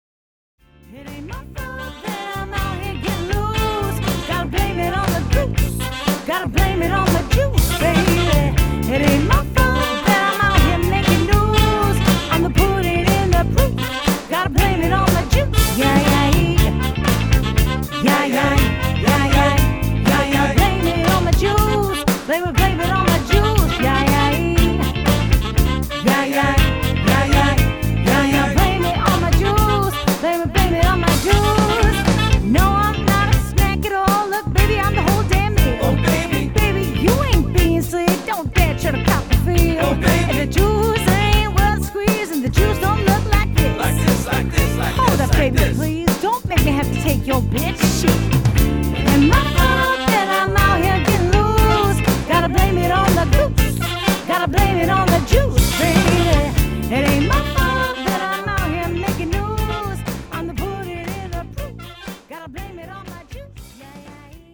the electrifying 7-piece band from Rochester